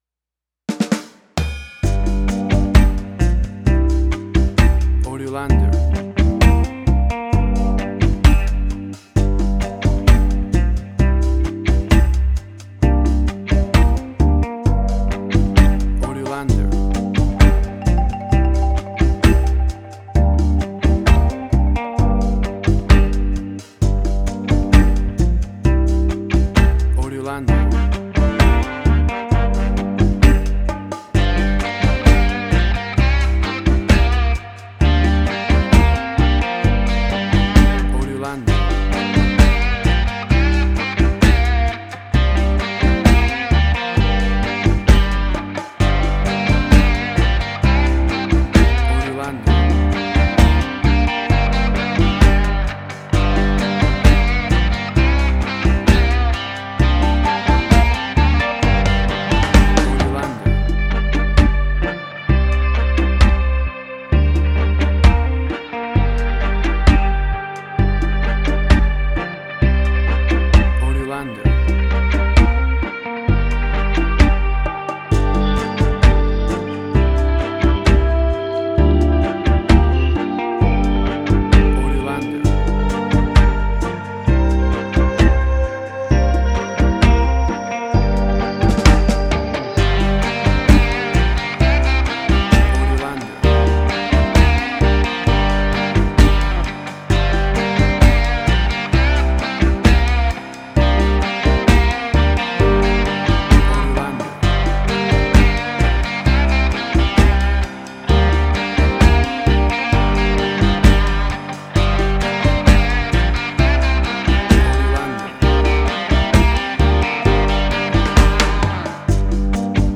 Reggae caribbean Dub Roots
Tempo (BPM): 65